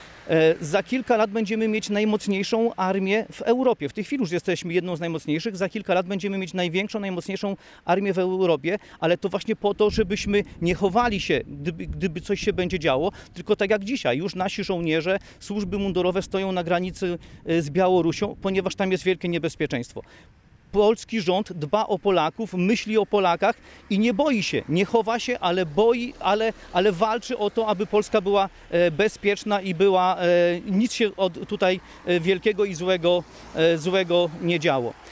Przed jednostką wojskową w Nisku odbyła się konferencja prasowa Prawa i Sprawiedliwości poświęcona sprawom bezpieczeństwa Polski i rozwoju jednostek wojskowych w naszym Regionie.